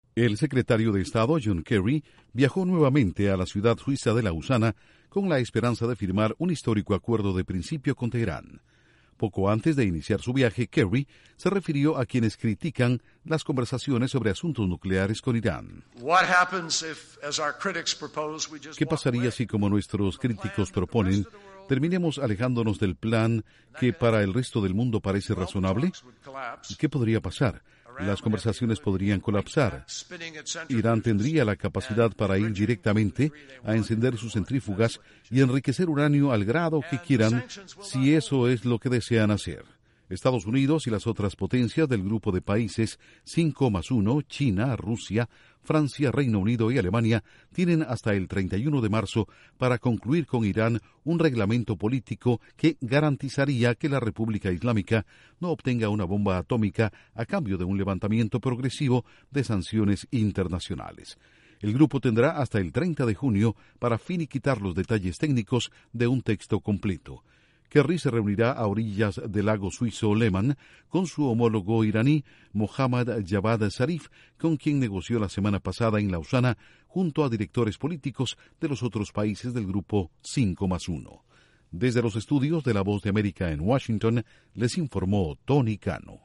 John Kerry regresa a Suiza cuando faltan pocos días para que se cumpla la fecha límite para lograr un acuerdo internacional sobre el programa nuclear de Irán. Informa desde los estudios de la Voz de América en Washington